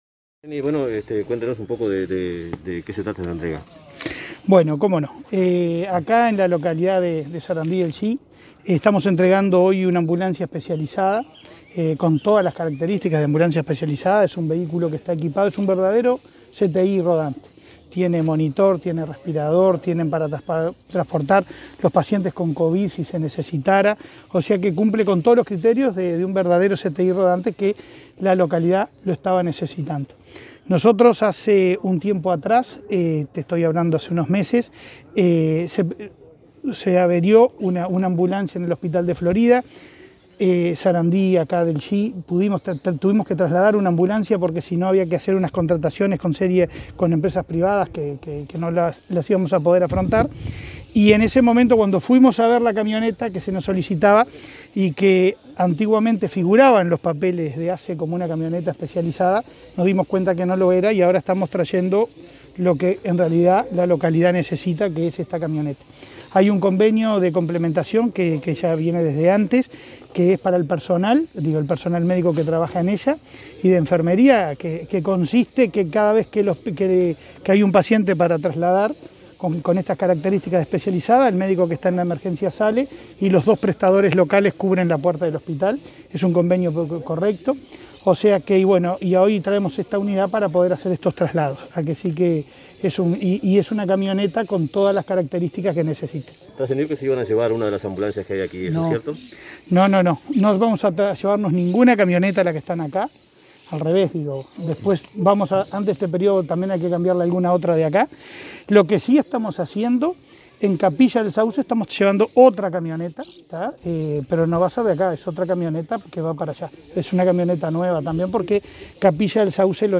Declaraciones del presidente de ASSE, Leonardo Cipriani
Declaraciones del presidente de ASSE, Leonardo Cipriani 11/03/2021 Compartir Facebook X Copiar enlace WhatsApp LinkedIn Declaraciones del presidente de ASSE, Leonardo Cipriani, tras entregar una ambulancia altamente especializada en el Centro Auxiliar de Salud de Sarandi del Yí, Durazno.